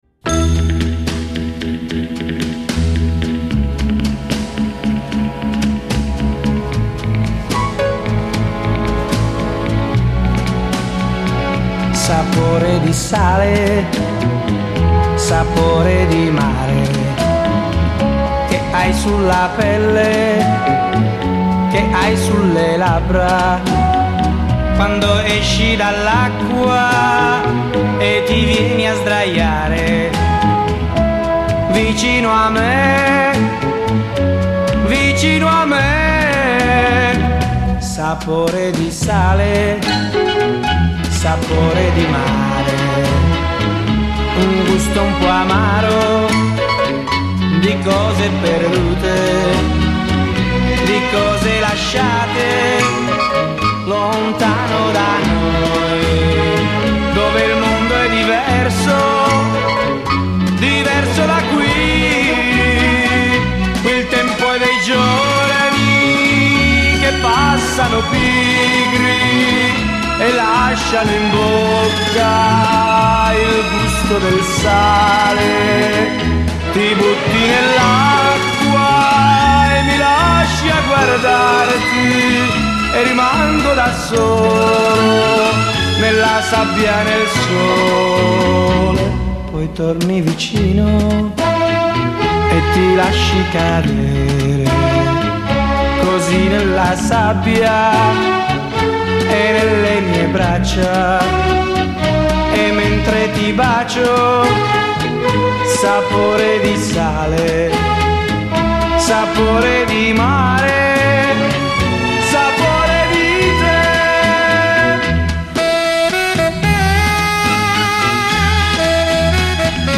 Incontro con il polistrumentista e compositore ticinese